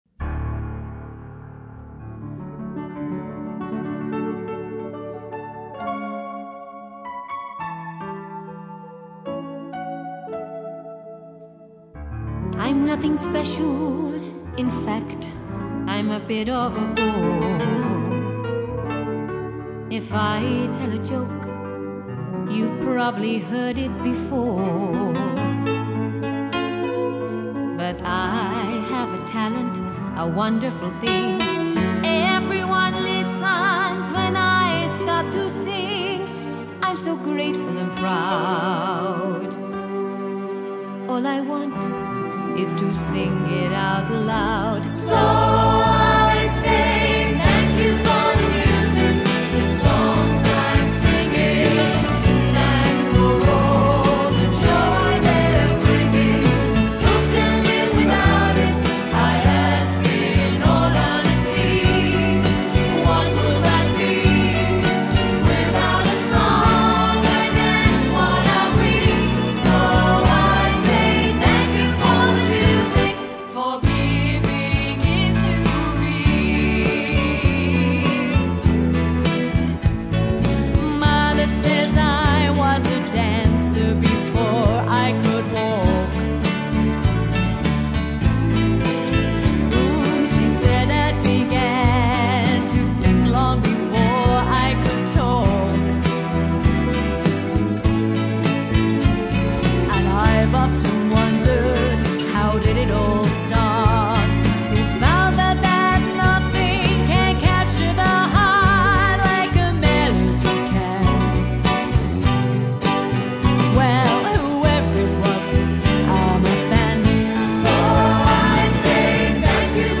a 5 piece live band with 5 part harmonies